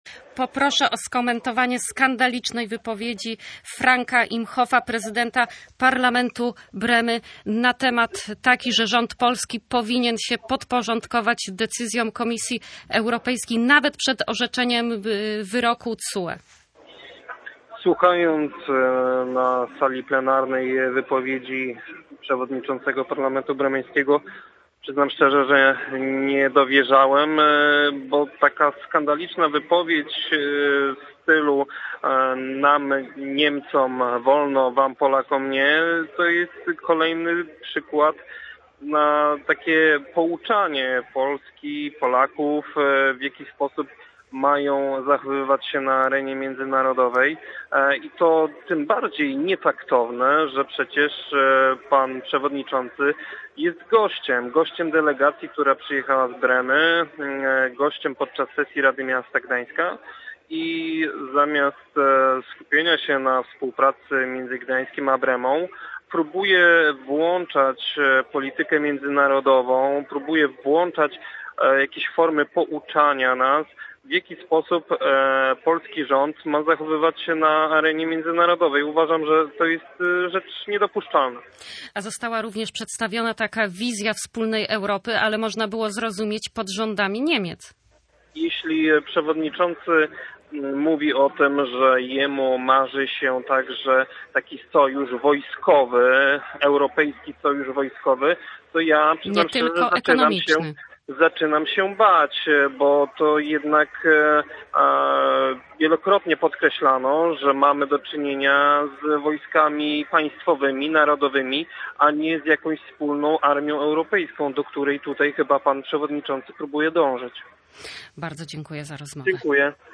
Wystąpienie prezydenta parlamentu Bremy skomentował Przemysław Majewski, radny miasta Gdańska.